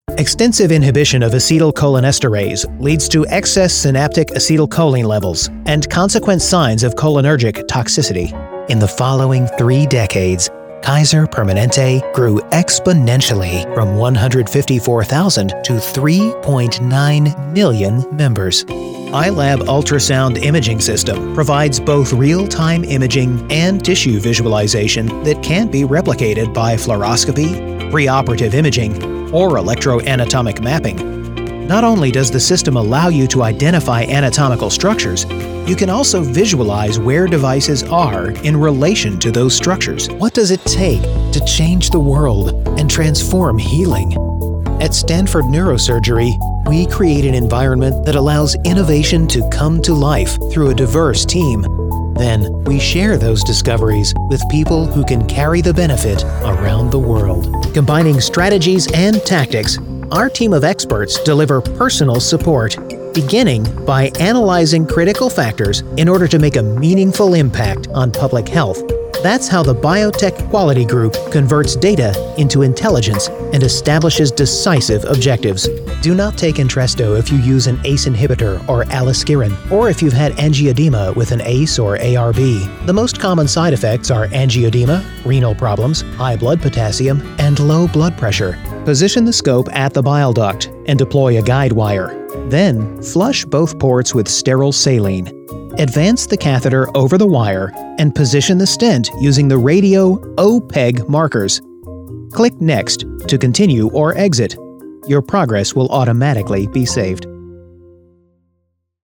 home studio, production, commercial, animation, video games, audiobooks
mid-atlantic
Sprechprobe: Industrie (Muttersprache):